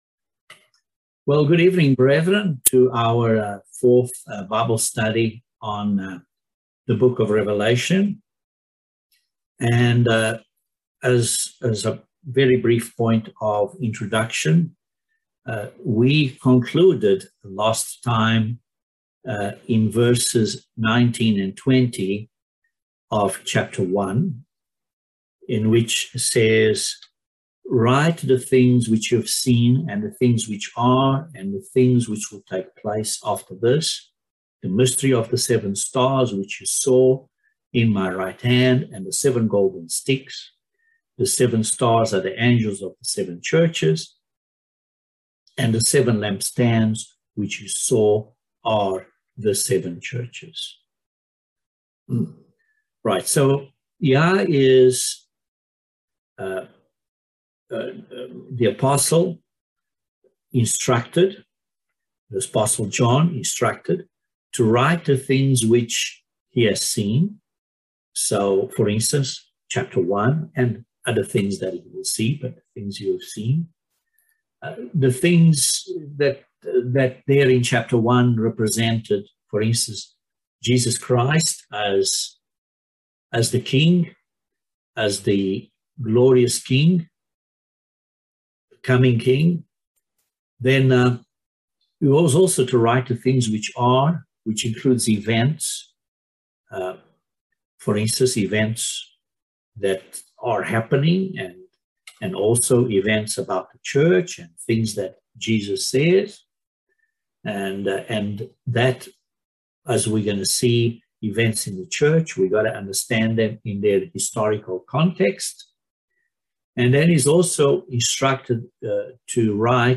Bible Study no 4 of Revelation